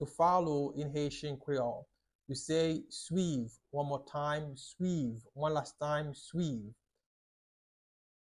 Pronunciation:
10.How-to-say-To-follow-in-Haitian-Creole-–-Swiv-Pronunciation.mp3